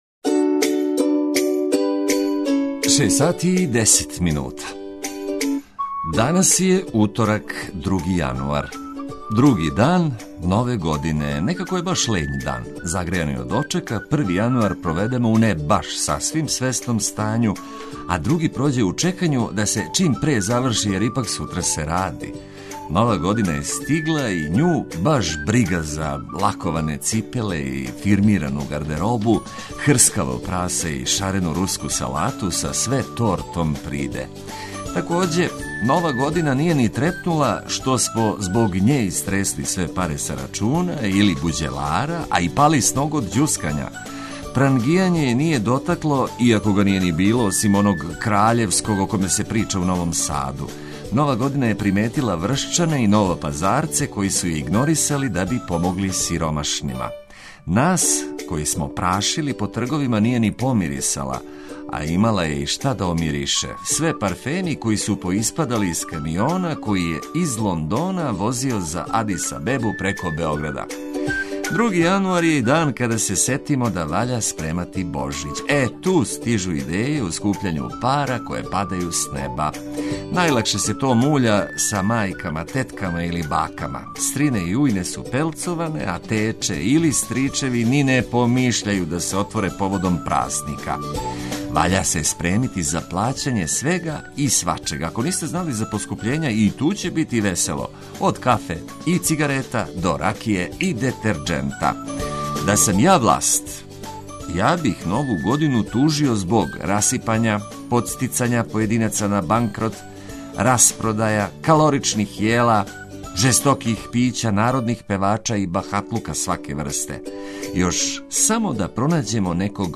Још увек је Супер Нова, а то значи информације, забава, музика, ведрина, оптимизам...